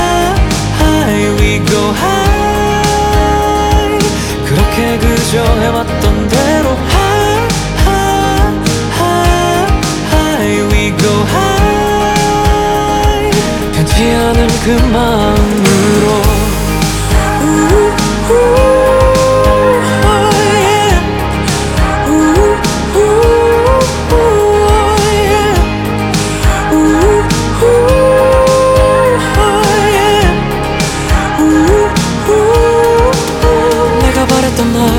K-Pop Pop
2023-06-19 Жанр: Поп музыка Длительность